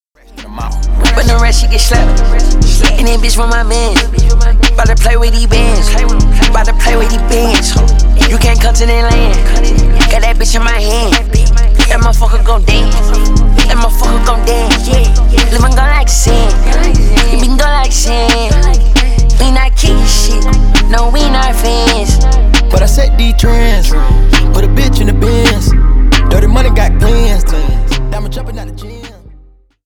Рэп и Хип Хоп